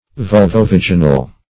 Search Result for " vulvovaginal" : The Collaborative International Dictionary of English v.0.48: Vulvovaginal \Vul`vo*vag"i*nal\, a. (Anat.)
vulvovaginal.mp3